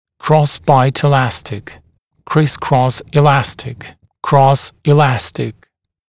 [‘krɔsbaɪt ɪ’læstɪk] [krɪs-krɔs ɪ’læstɪk] [krɔs ɪ’læstɪk] [‘кросбайт и’лэстик] [крис-крос и’лэстик] [крос и’лэстик] перекрестная эластичная тяга